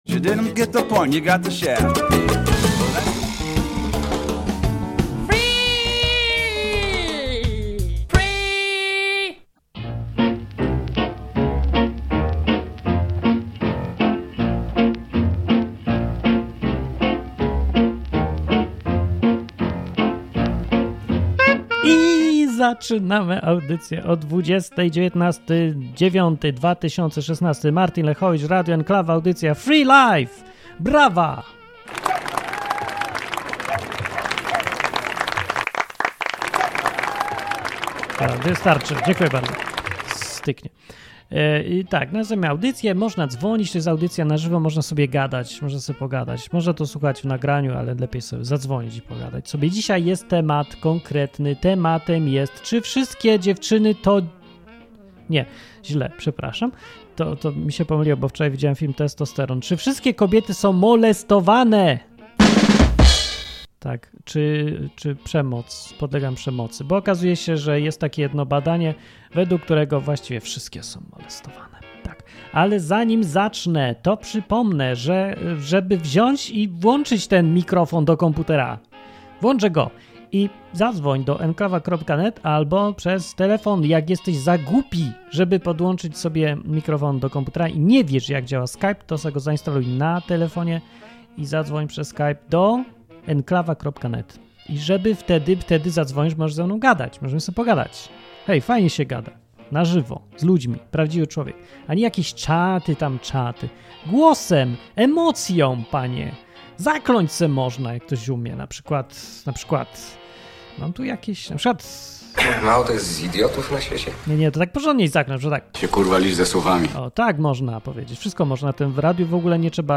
W tej audycji usłyszycie przede wszystkim zdanie kobiet (zwłaszcza jednej).
Program dla wszystkich, którzy lubią luźne, dzikie, improwizowane audycje na żywo.